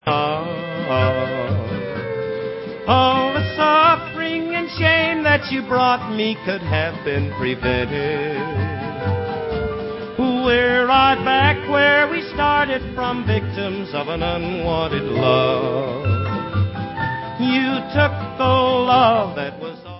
sledovat novinky v oddělení Rockabilly/Psychobilly